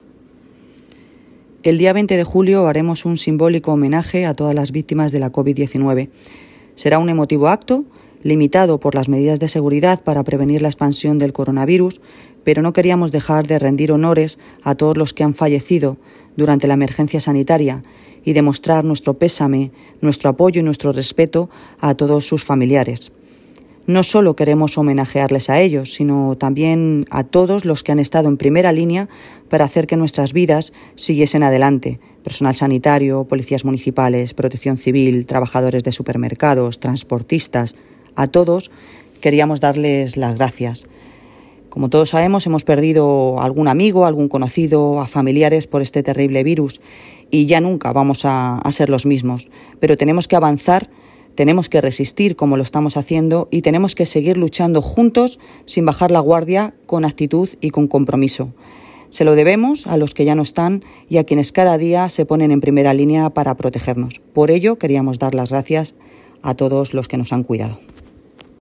Alcaldesa de Móstoles Noelia Posse presentación Homenaje